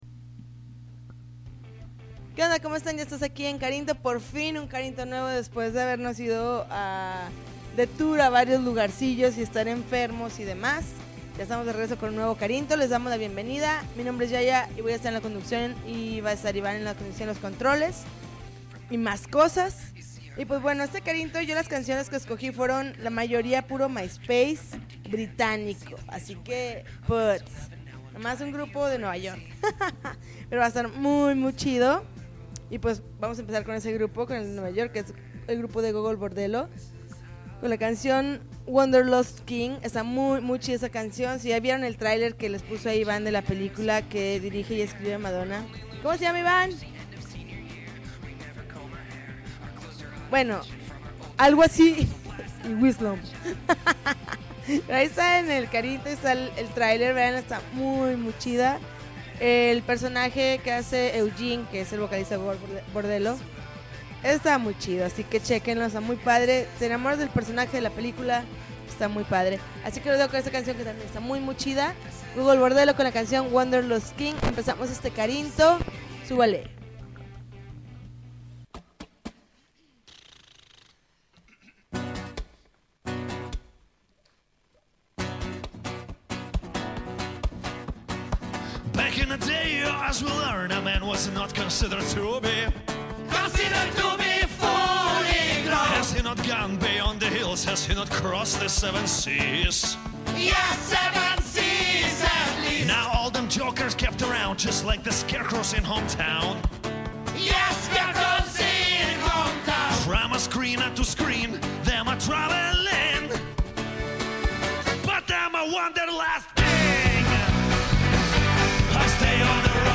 March 28, 2010Podcast, Punk Rock Alternativo